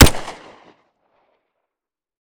weap_beta_fire_plr_01.ogg